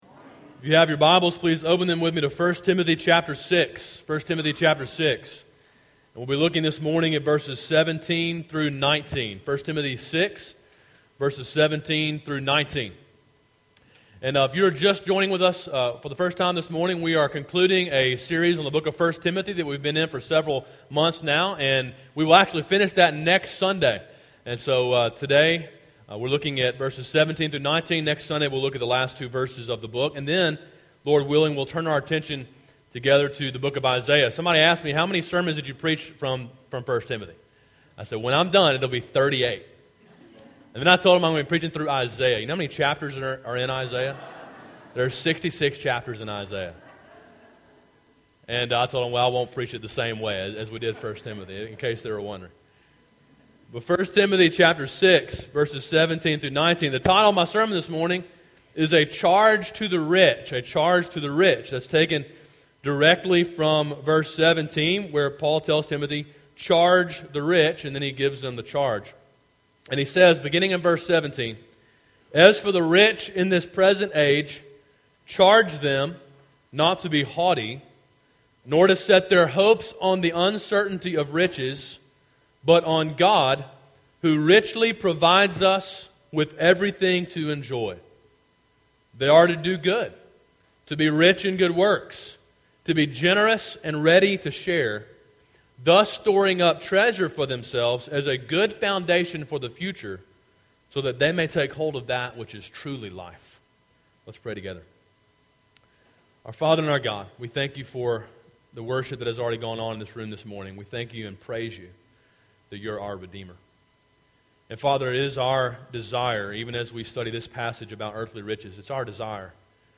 A sermon in a series on the book of 1 Timothy.